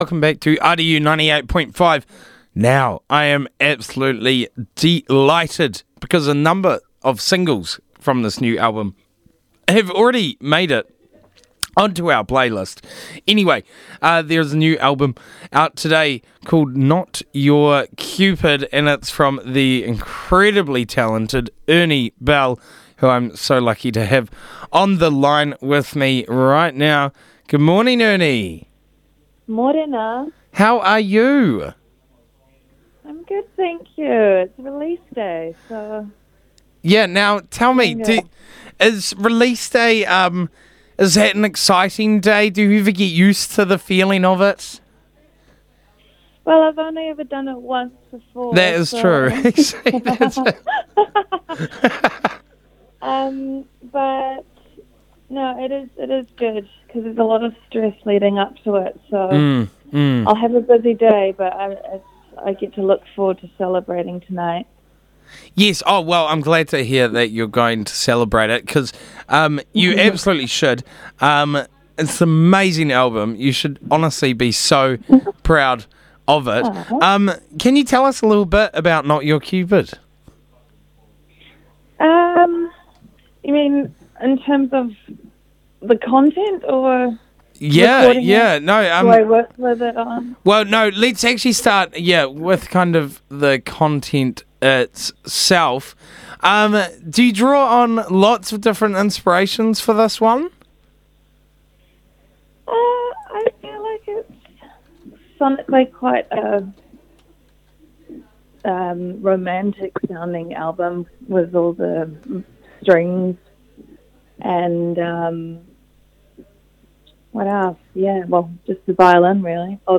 She dialed into Burnt Breakfast